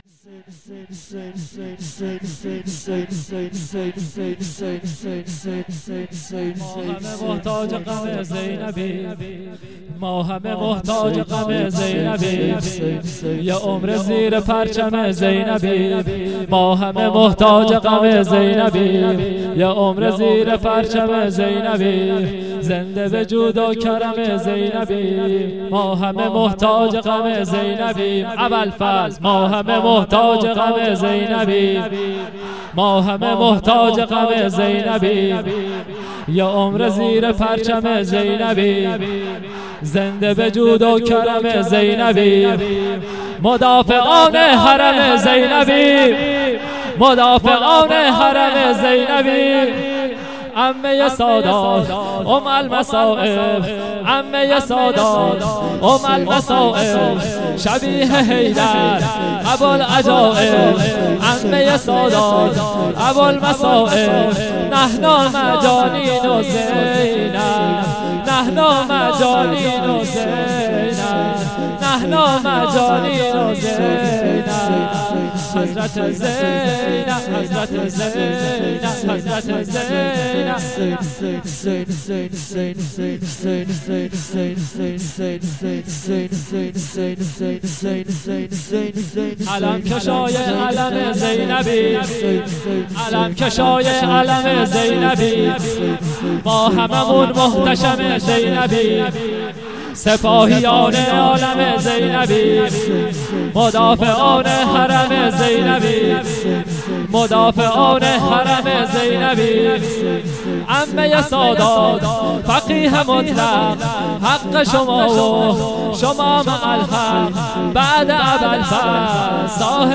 خیمه گاه - هیئت قتیل العبرات - مداحی